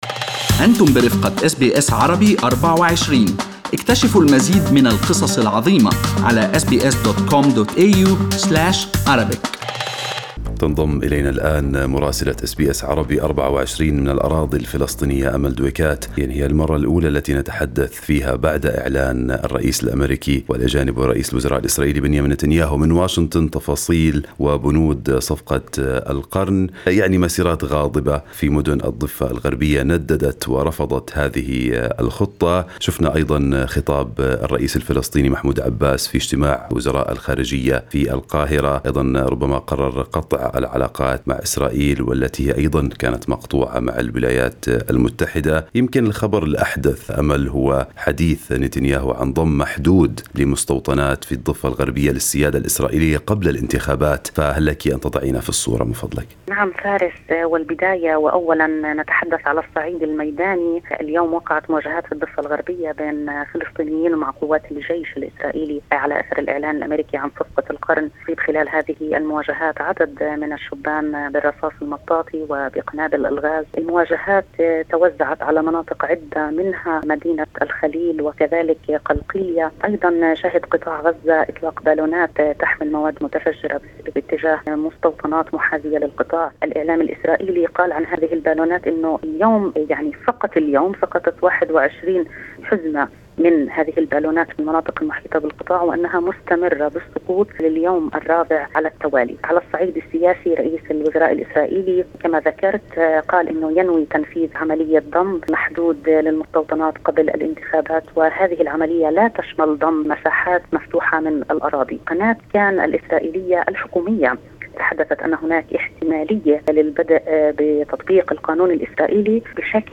أهم أخبار الدول العربية مع مراسلينا من لبنان ومصر والأراضي الفلسطينية والعراق والولايات المتحدة.